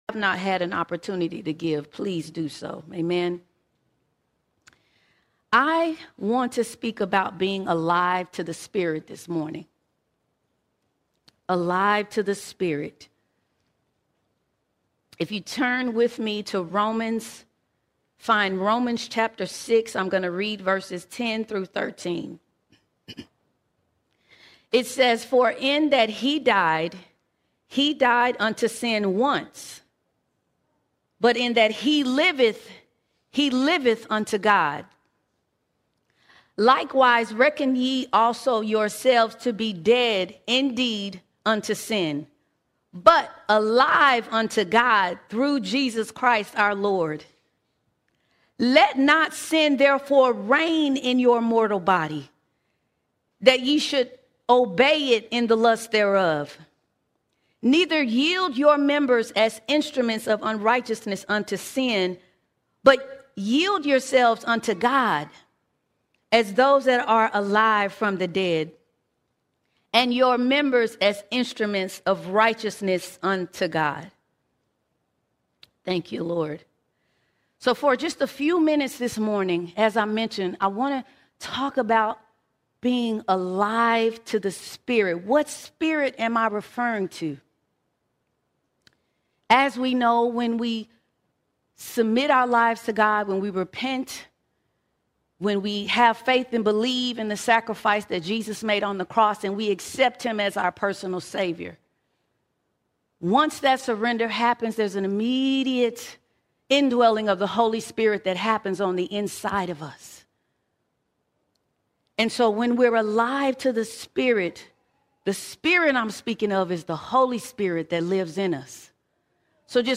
10 November 2025 Series: Sunday Sermons All Sermons Alive To The Spirit Alive To The Spirit When we become alive to the Spirit, something changes and then comes a charge.